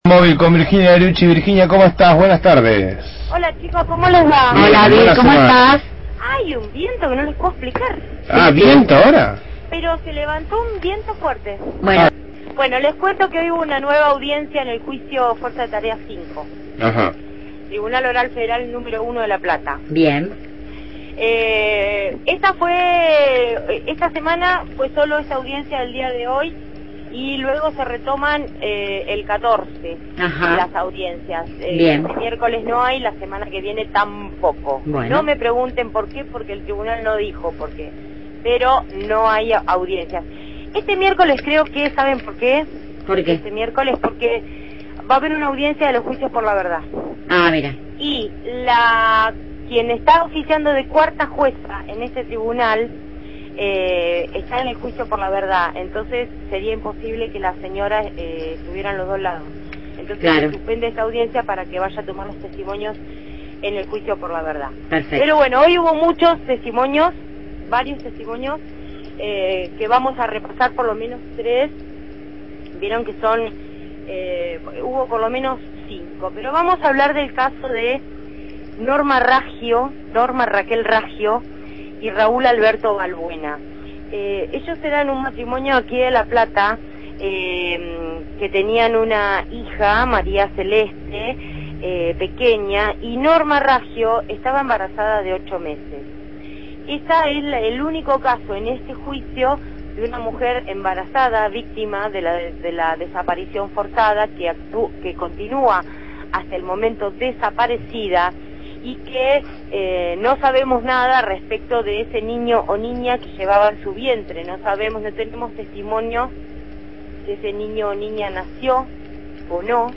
MÓVIL/ Juicio por crímenes de lesa humanidad – Radio Universidad